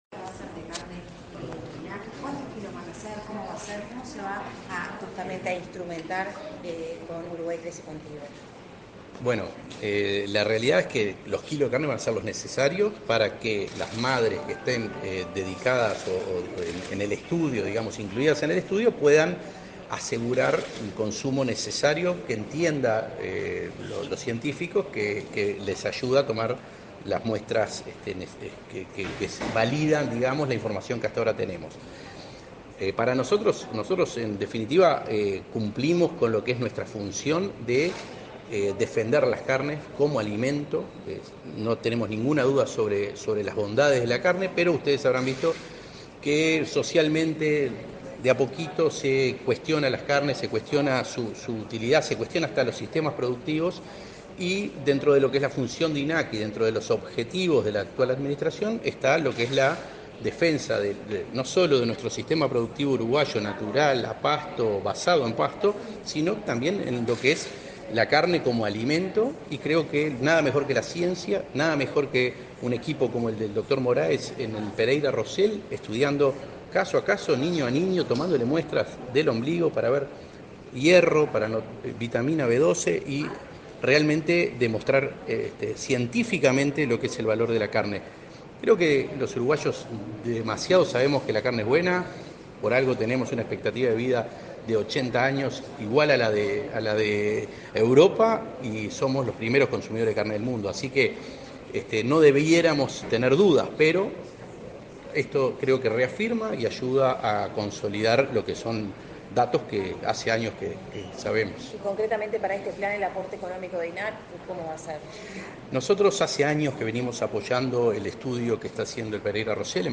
Declaraciones a la prensa del presidente del INAC, Conrado Ferber
Tras participar en la firma de convenio entre el Ministerio de Desarrollo Social (Mides) y el Instituto Nacional de Carne (INAC), este 6 de febrero,